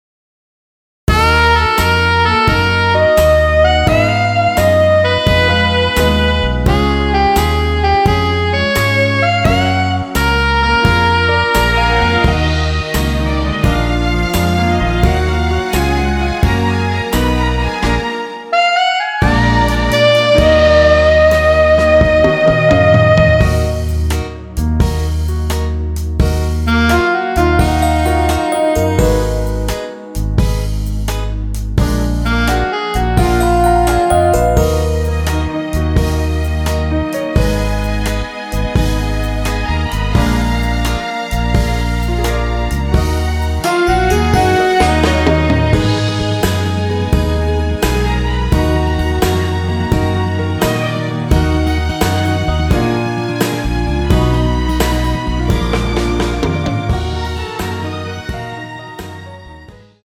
Ebm
앞부분30초, 뒷부분30초씩 편집해서 올려 드리고 있습니다.
중간에 음이 끈어지고 다시 나오는 이유는